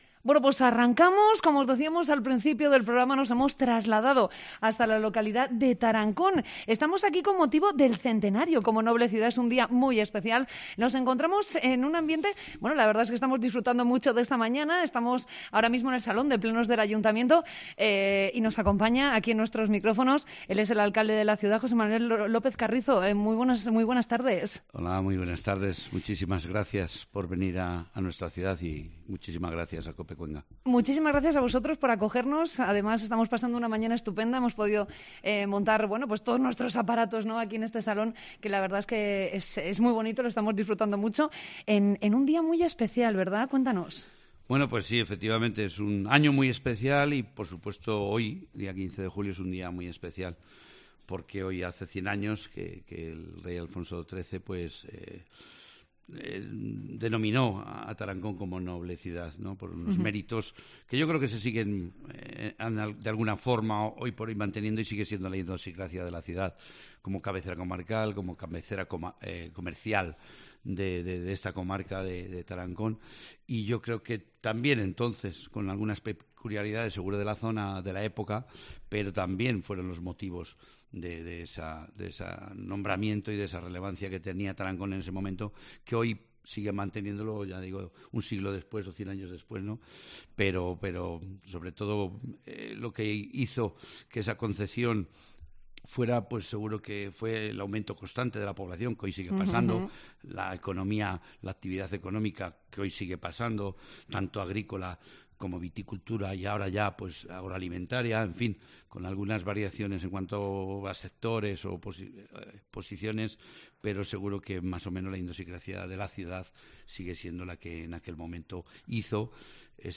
Entrevista con el alcalde de Tarancón, José Manuel López Carrizo